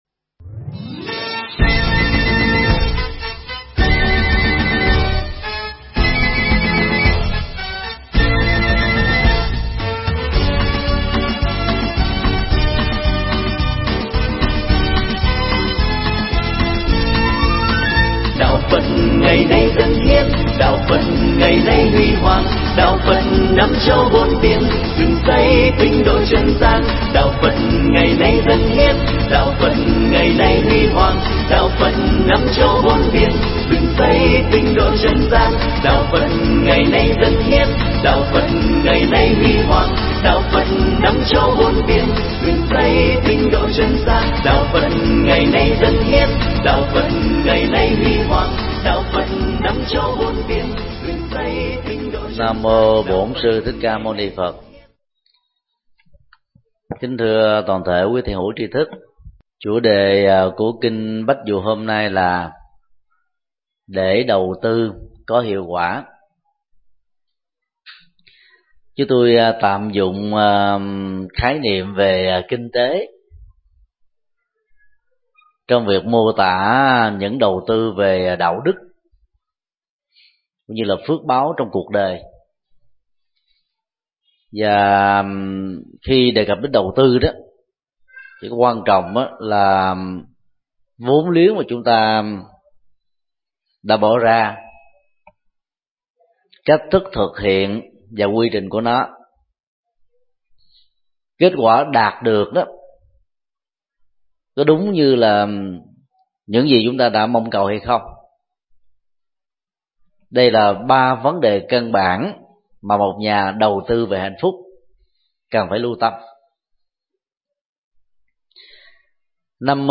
Mp3 Pháp Thoại Kinh Bách Dụ 10
giảng tại chùa Giác Ngộ